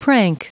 Prononciation du mot prank en anglais (fichier audio)
Prononciation du mot : prank